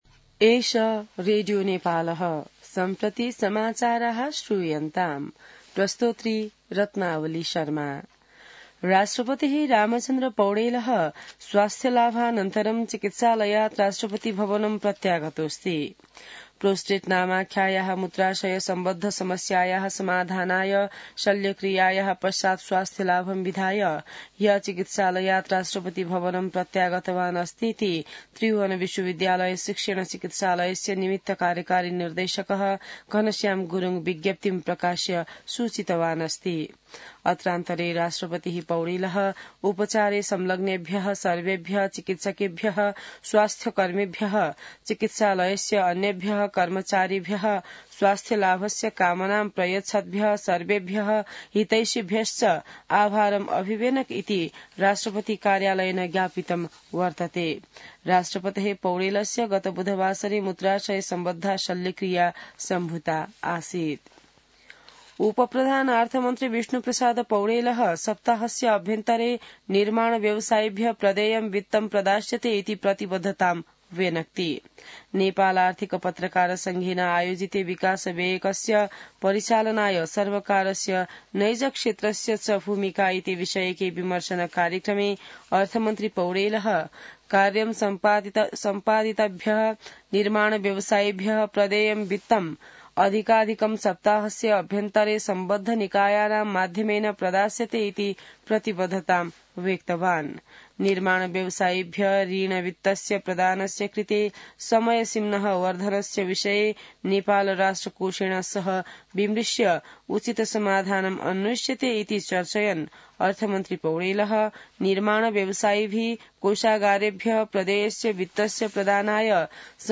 संस्कृत समाचार : २४ मंसिर , २०८१